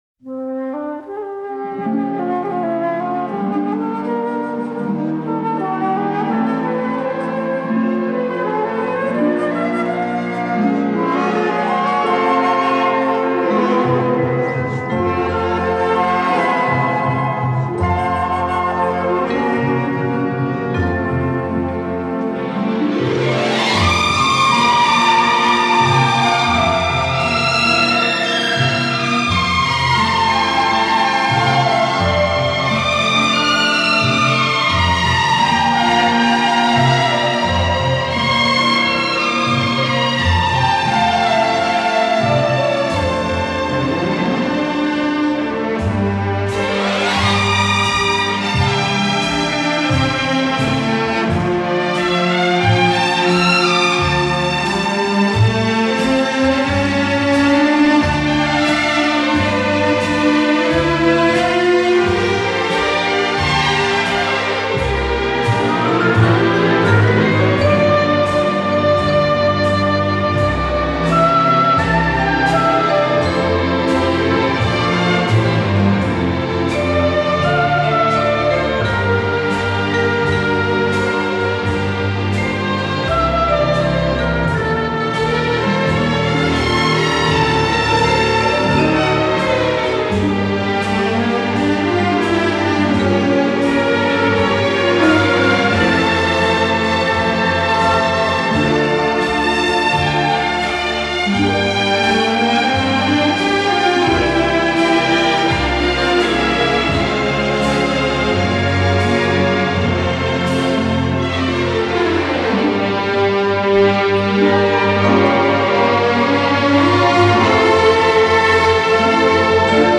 Genre:Easy Listening